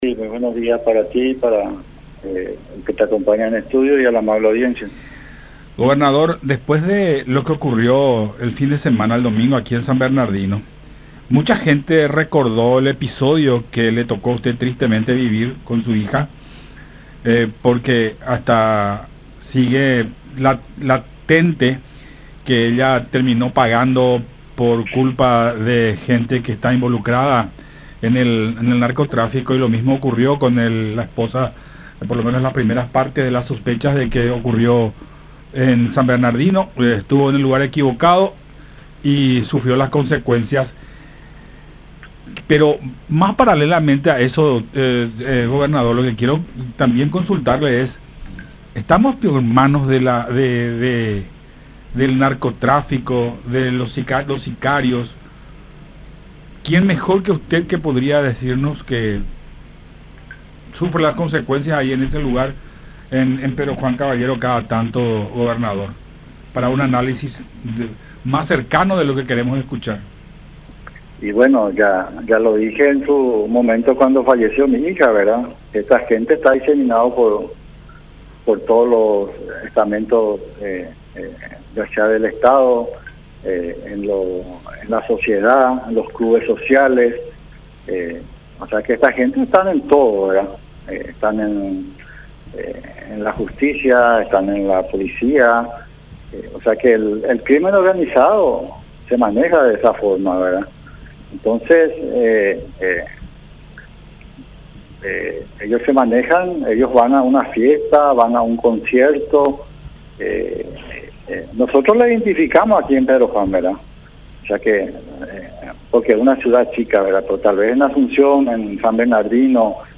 en charla con Todas Las Voces por La Unión